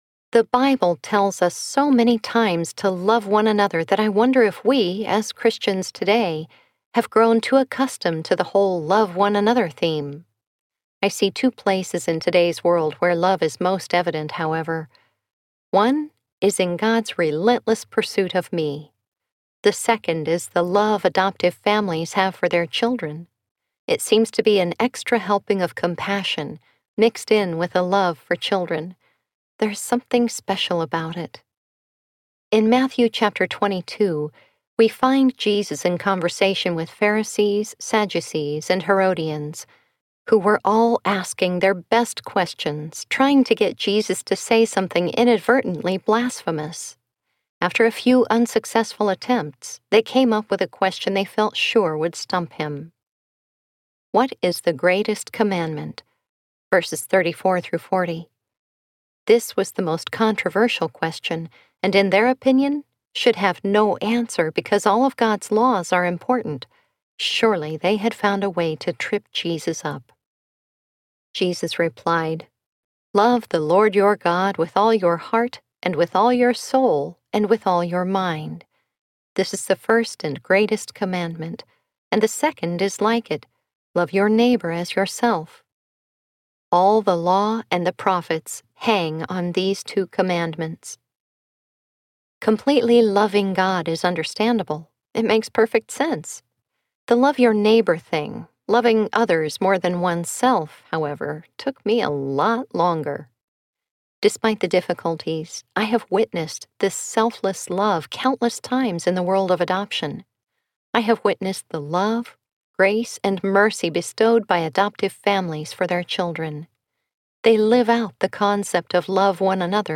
I Call You Mine Audiobook
Narrator